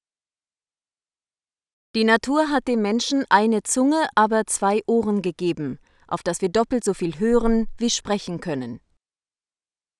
female.wav